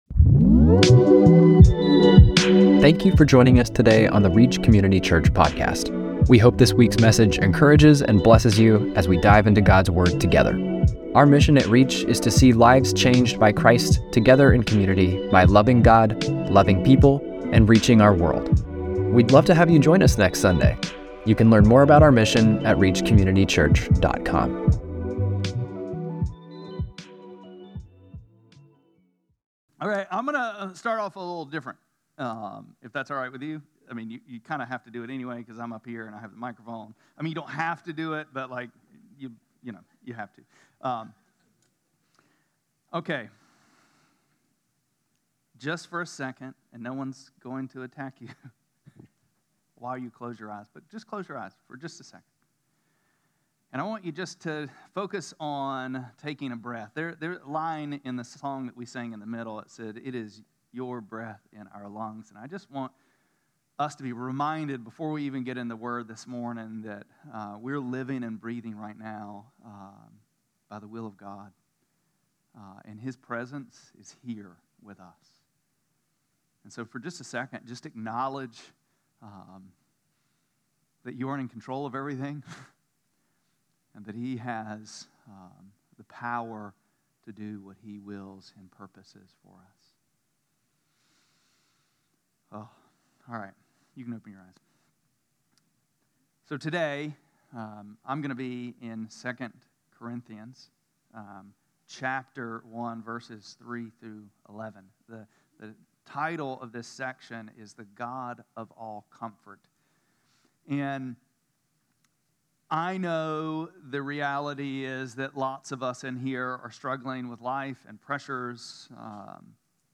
9-7-25-Sermon.mp3